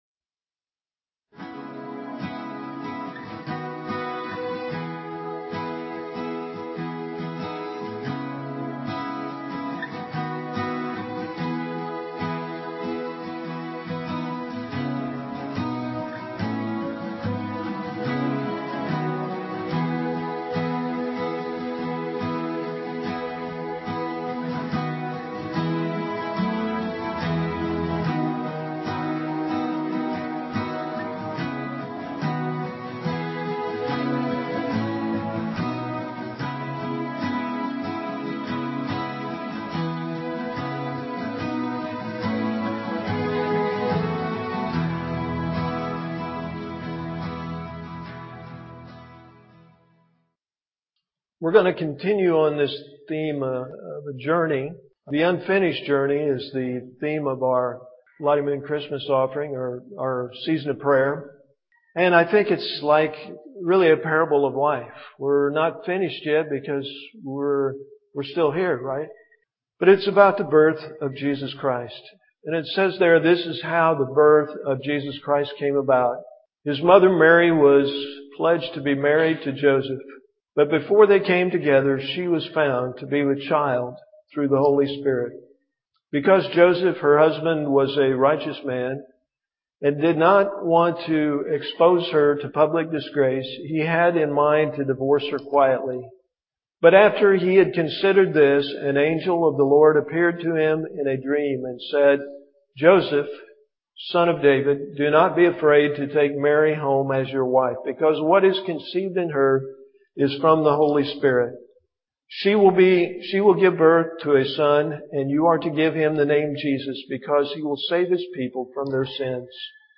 PLAY: A Christmas Journey, Part 1, December 5, 2010 Scripture: Matthew 1:18-25. Message